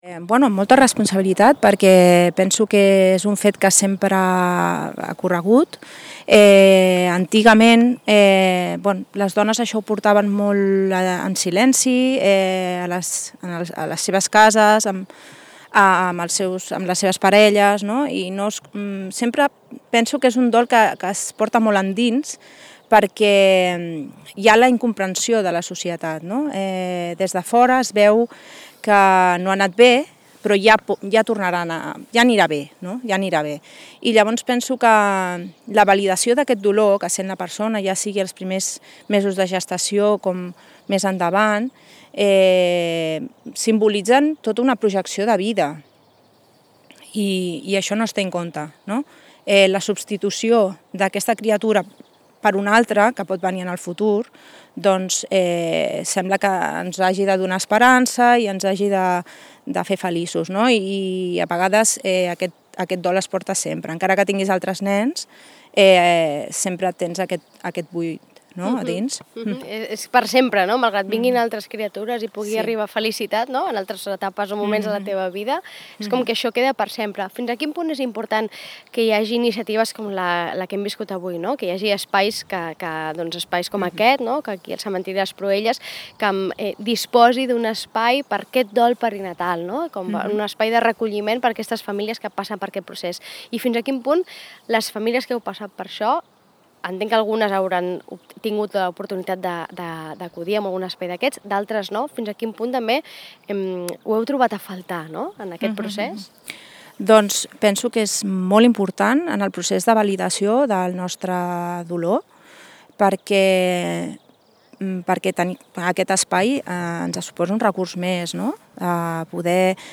Una conversa amb el dolor de fons però amb un reclam clar, la validació d’aquest dolor malgrat sigui tan prematur.